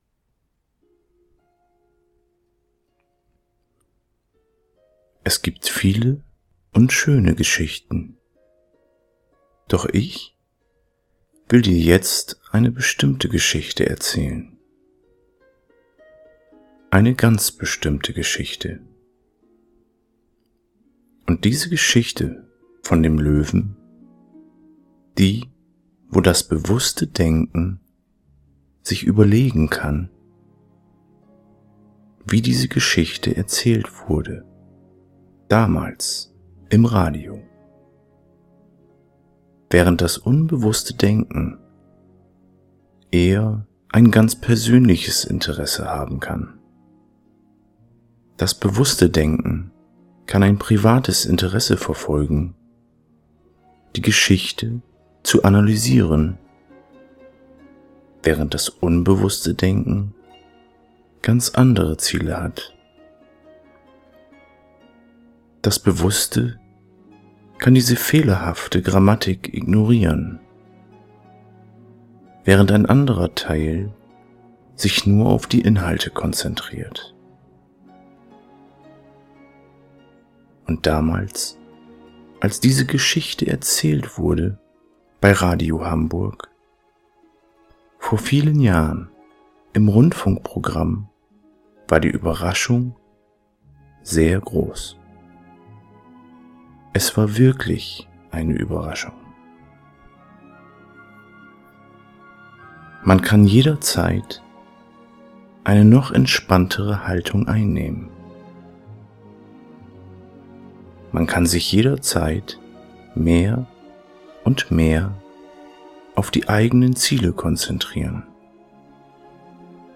Therapeutische Audio-Hypnose zur Selbstanwendung für junge Erwachsene: Erwachsen werden – Mit Spaß und Neugier.
• Methode: Klinische Hypnosetherapie nach Milton Erickson (mit bildhafter Metaphernarbeit)